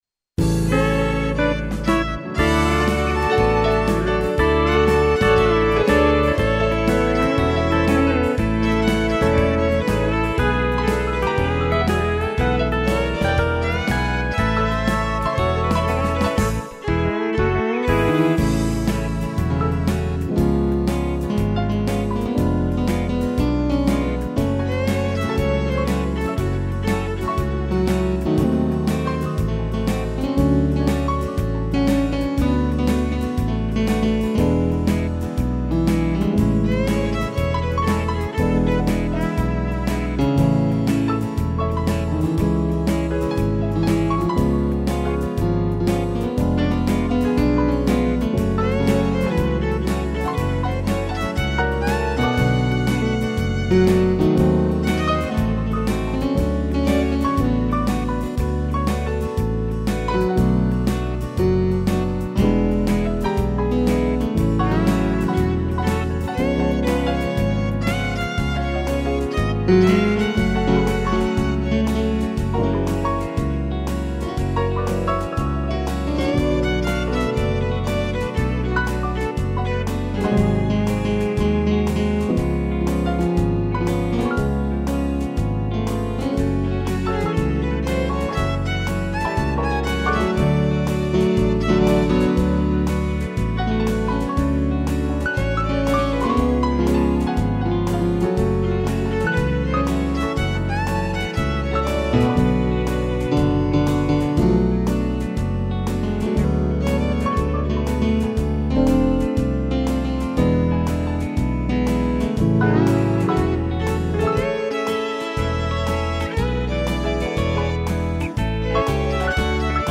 piano e violino